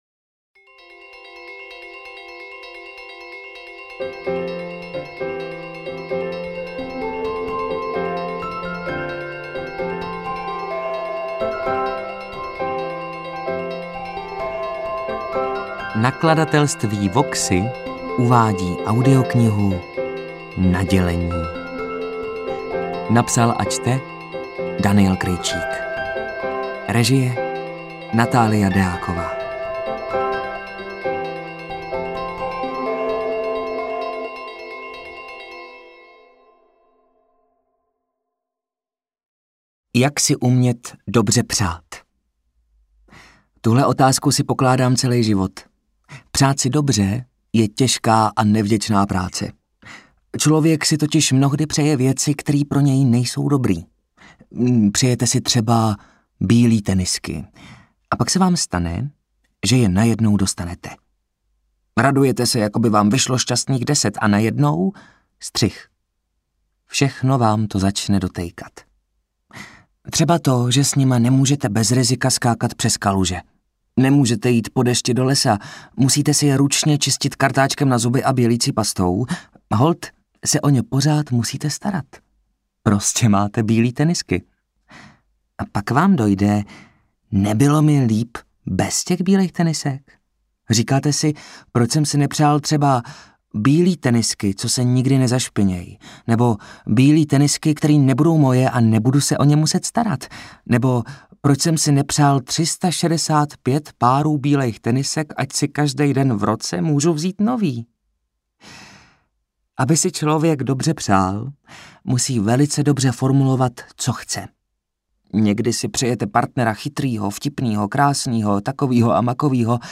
Interpret:  Daniel Krejčík
AudioKniha ke stažení, 30 x mp3, délka 5 hod. 31 min., velikost 301,3 MB, česky